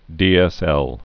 (dēĕsĕl)